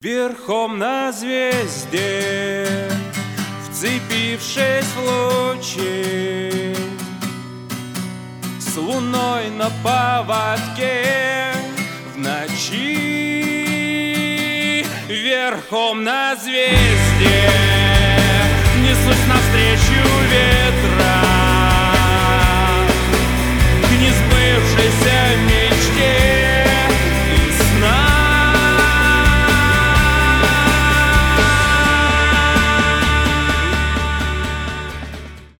indie rock
гитара , акустика